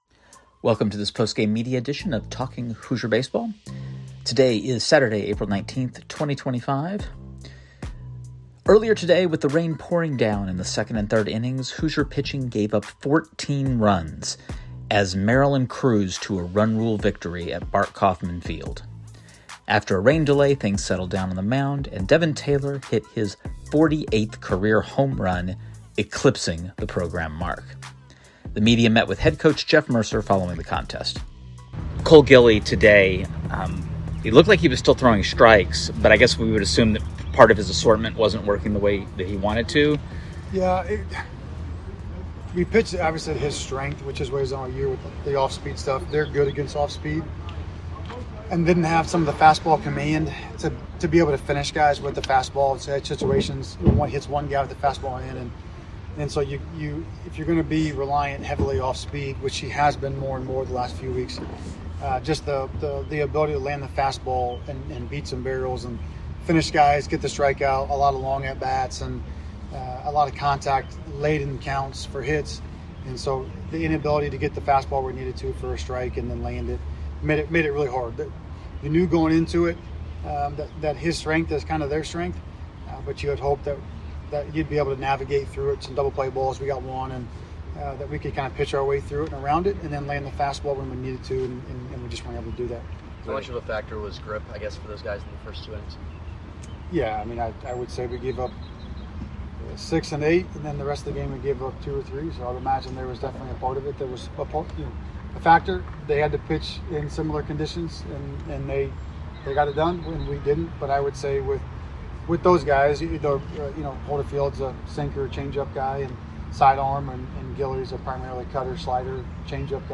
Postgame Media Maryland Saturday